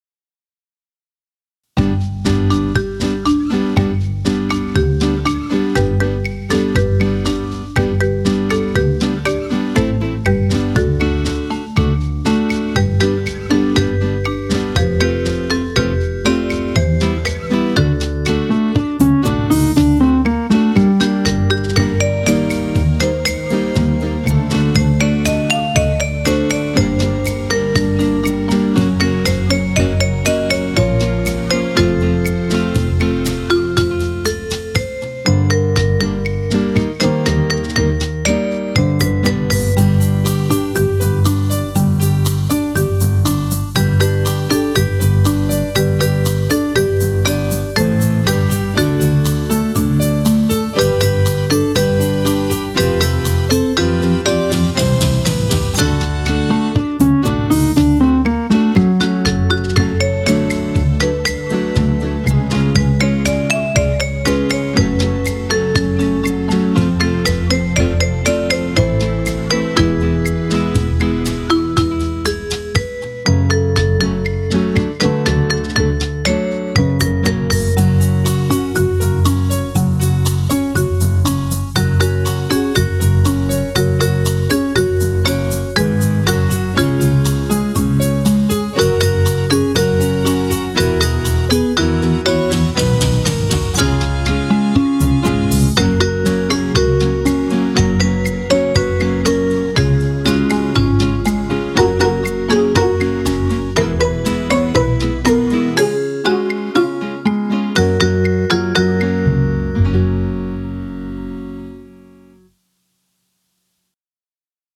restored legacy synthesizer